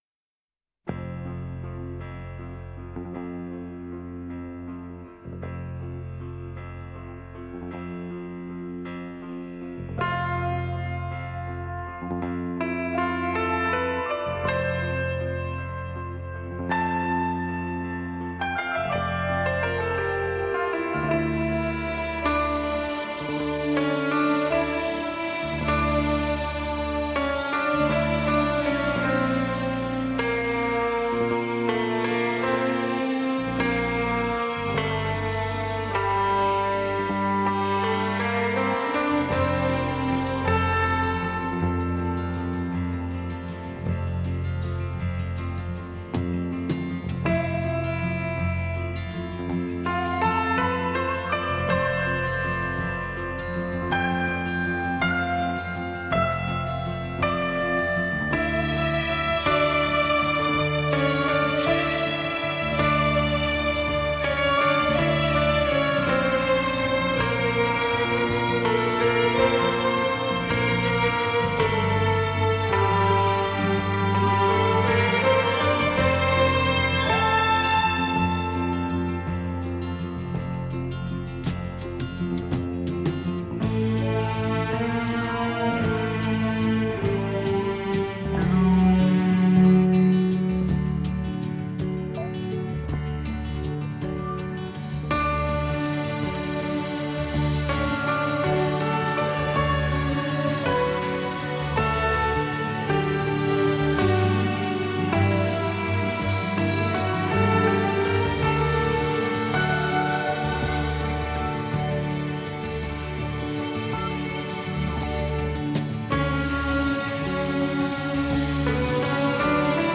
radiomarelamaddalena / STRUMENTALE / PIANO /